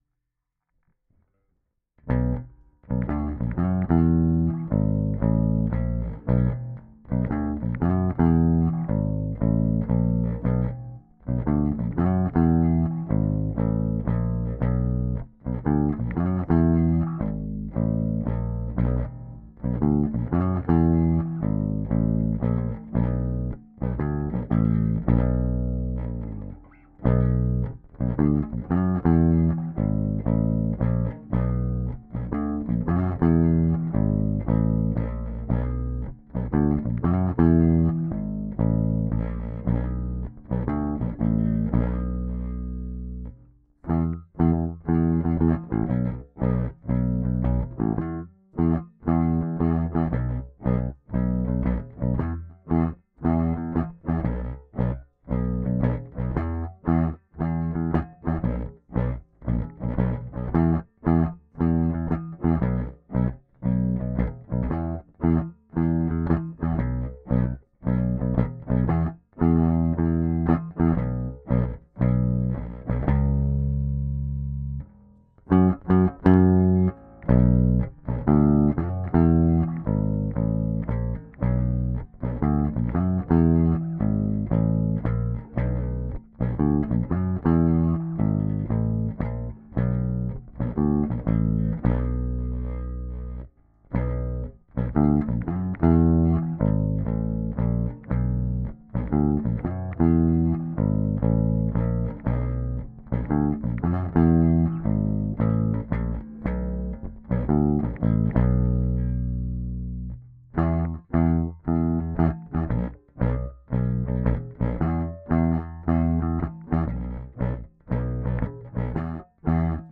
Equipment notes: played on an Aria SB1000, tone & volume full up, straight into the instrument input of my audio interface & thence to Reaper. No effects of any kind.
Now I listen to it isolated myself, it’s sloppy as all hell, and there do seem to be some odd clicks that I don’t understand… my excuse: this was a one-take on a first demo sketch…